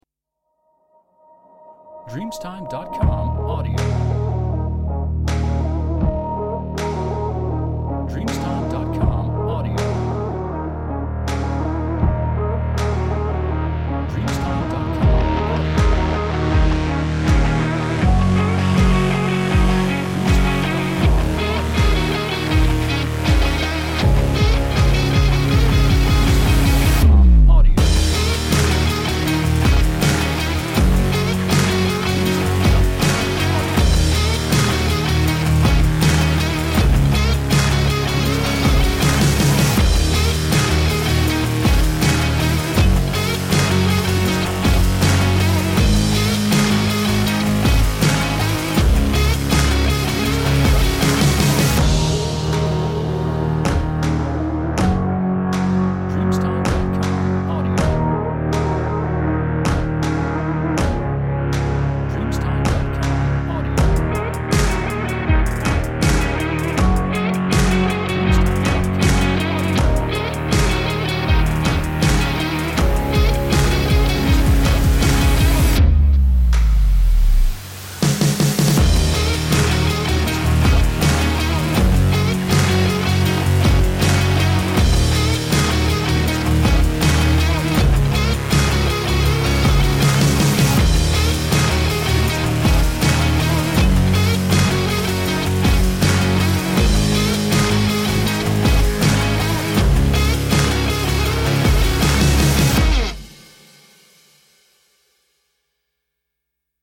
Stylish Rock